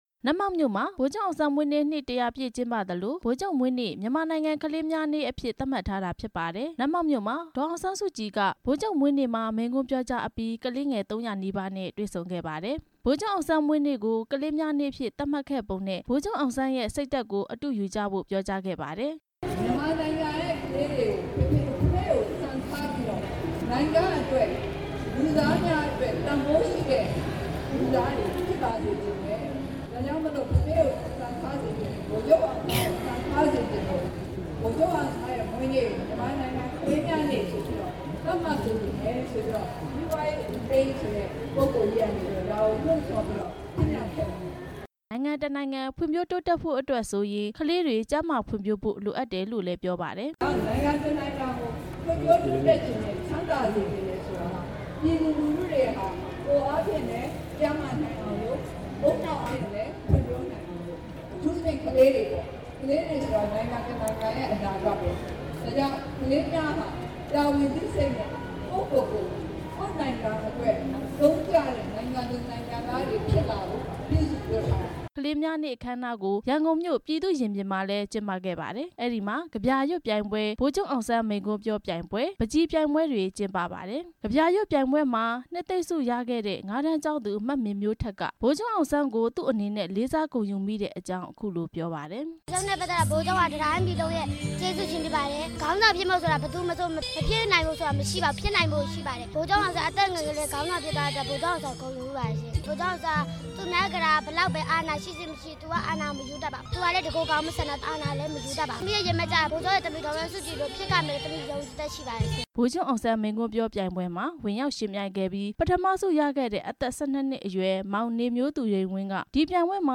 နတ်မောက်နဲ့ ရန်ကုန်မြို့ ပြည်သူ့ရင်ပြင်က ကလေးများနေ့အကြောင်း တင်ပြချက်